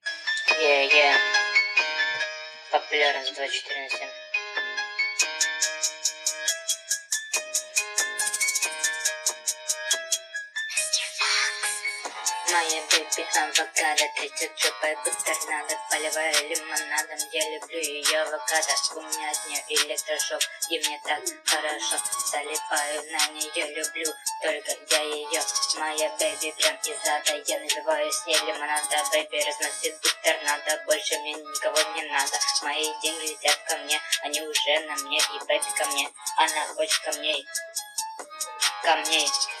• Качество: 128, Stereo
речитатив